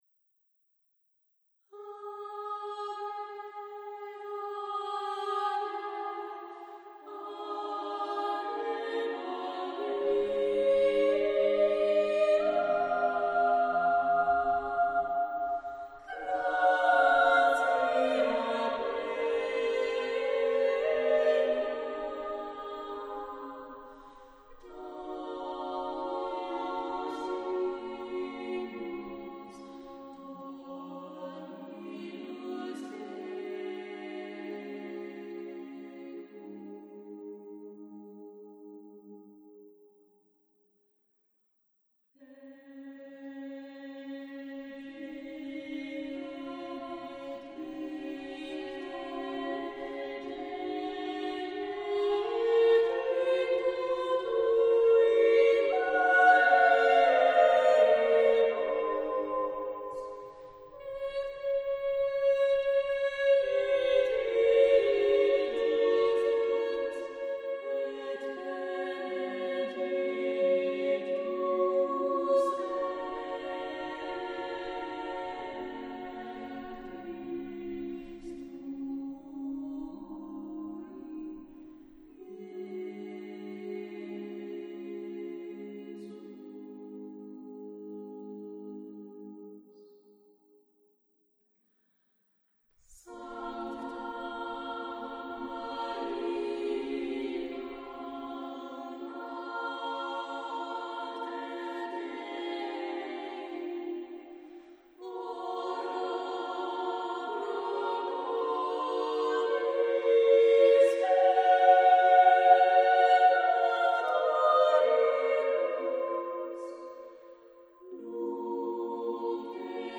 Voicing: SSAA a cappella